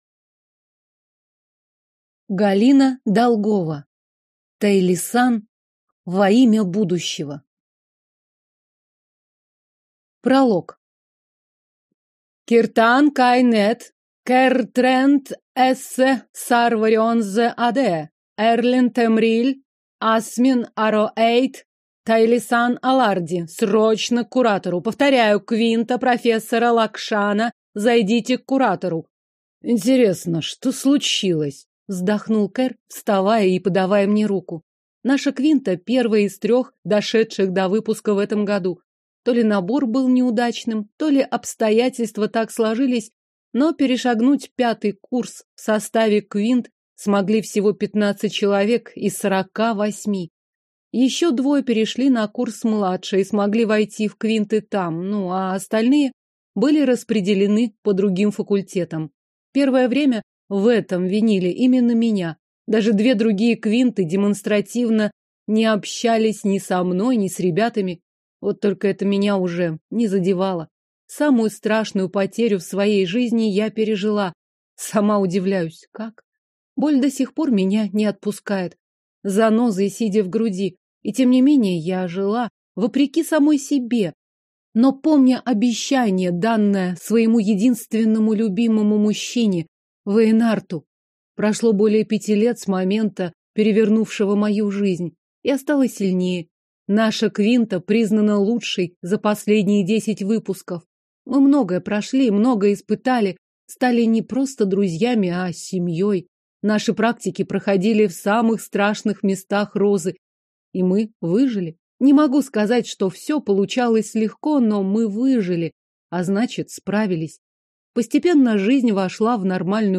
Аудиокнига Тайлисан. Во имя будущего - купить, скачать и слушать онлайн | КнигоПоиск